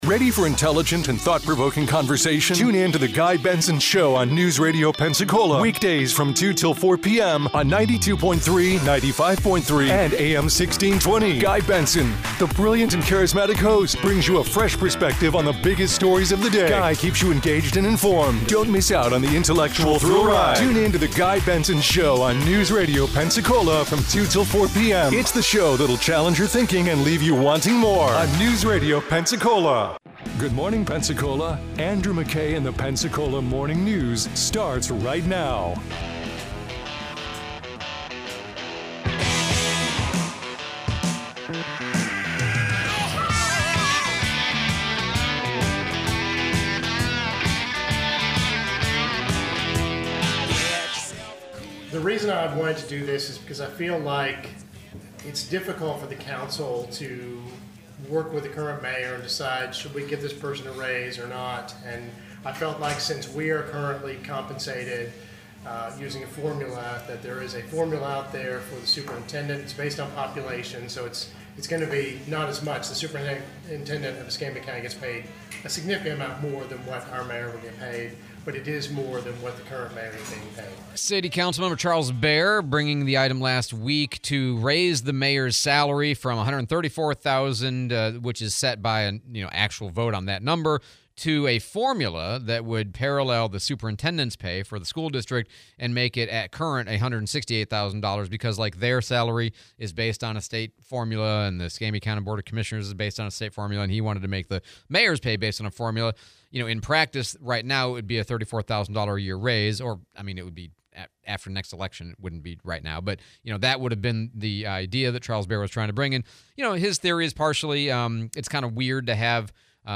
Debate over raise for Pensacola Mayor, interview with Mayor DC Reeves